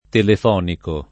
[ telef 0 niko ]